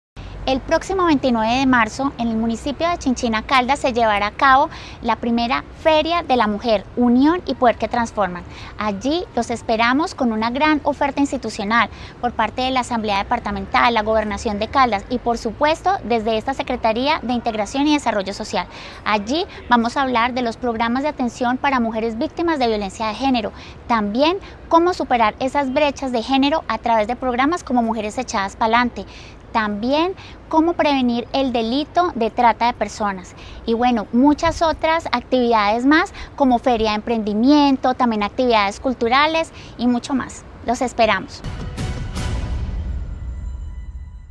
Secretaria de Integración y Desarrollo Social de Caldas, Sandra Patricia Álvarez.